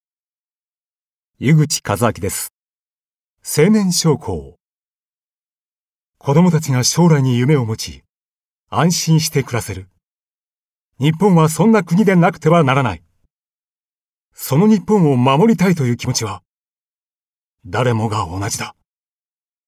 ナレーション
落ち着いた低音から、ソフト、クール、パワフルまで！